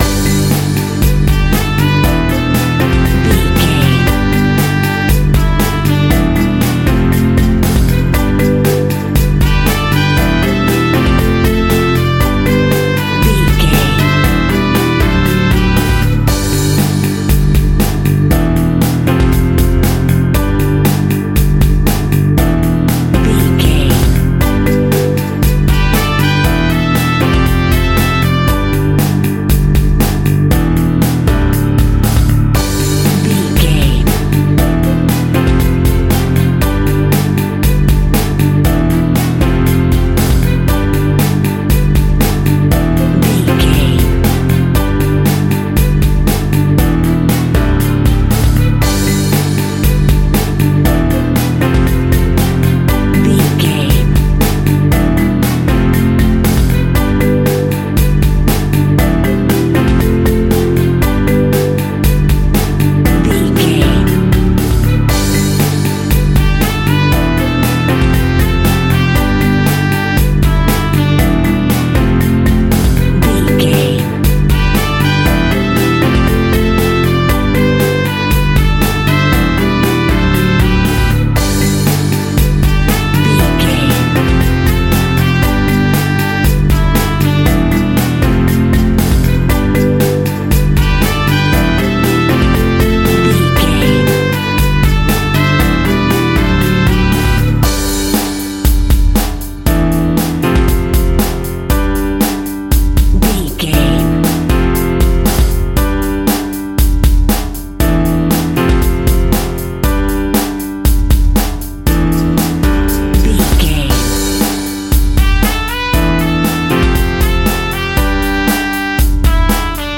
Ionian/Major
pop rock
indie pop
fun
energetic
uplifting
drums
bass guitar
piano
hammond organ
synth